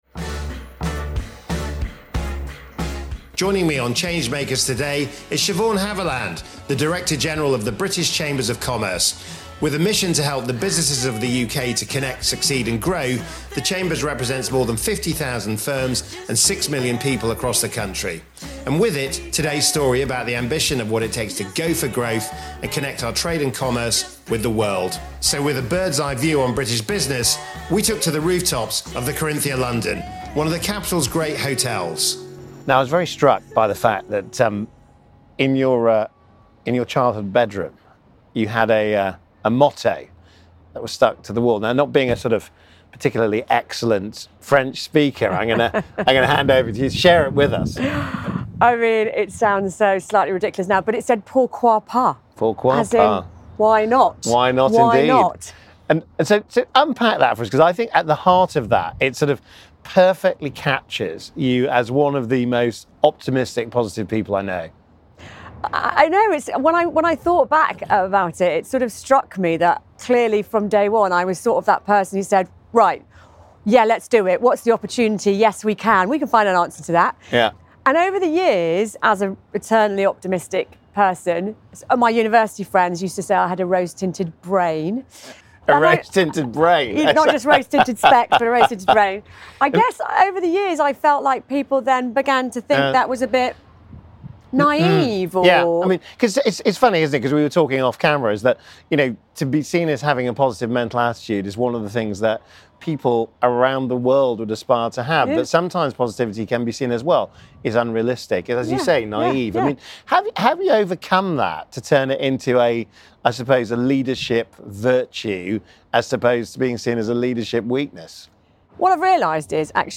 On the roof of the Corinthia hotel in the heart of the capital